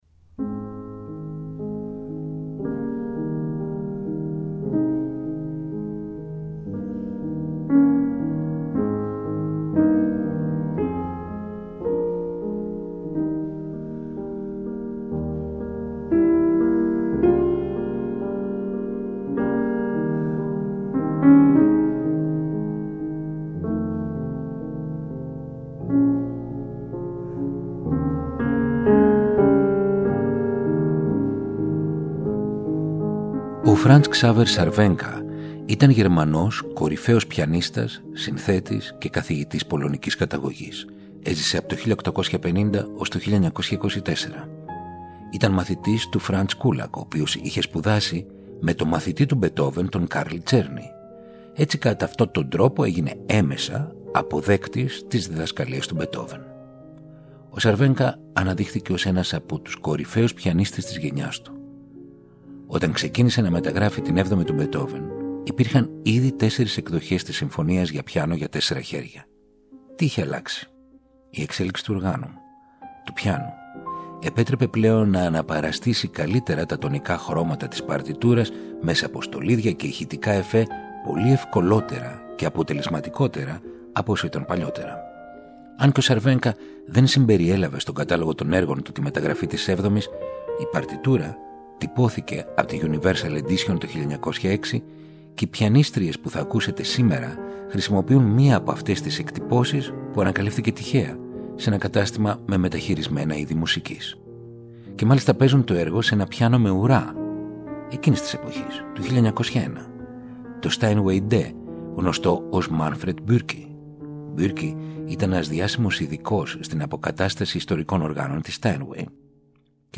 Η 7η στη μεταγραφή για πιάνο, για 4 χέρια του Φραντς Ξάβερ Σαρβένκα και η «Ωδή στη χαρά» από την 9η για τζαζ τρίο. Οι συμφωνίες του Μπετόβεν αλλιώς.